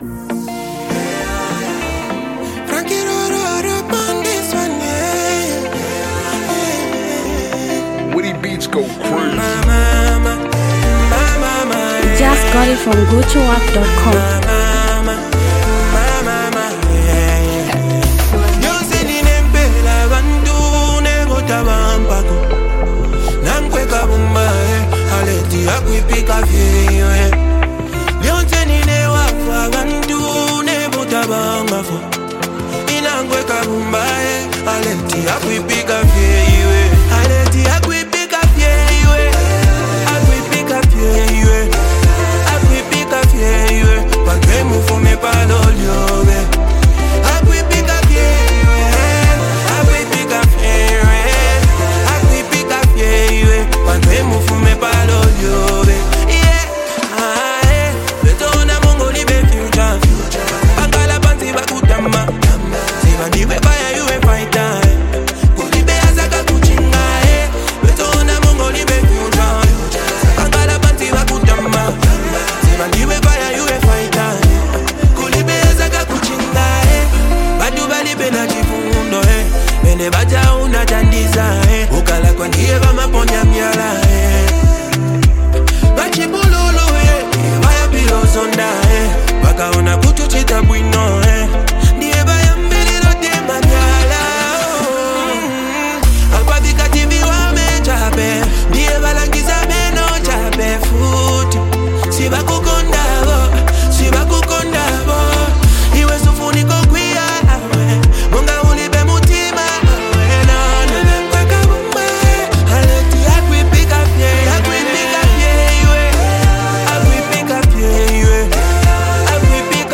Zambian Mp3 Music
powerful melodic sound